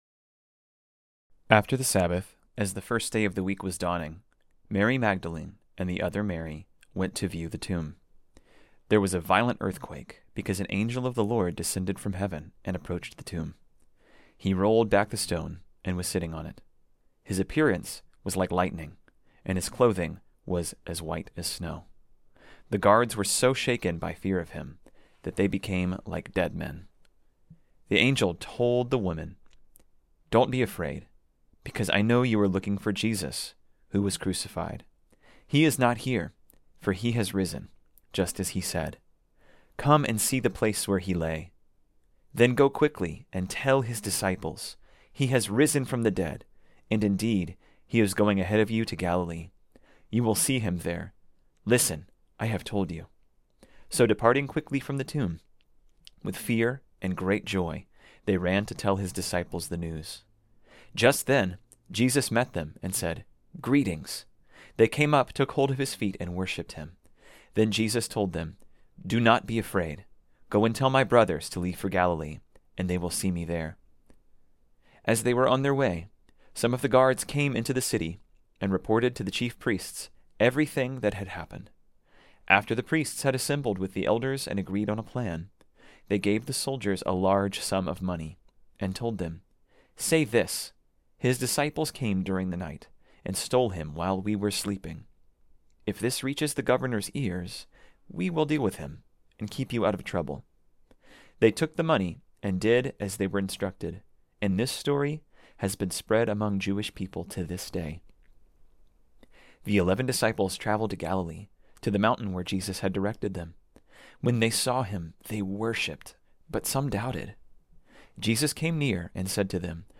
This sermon was originally preached on Sunday, December 1, 2024.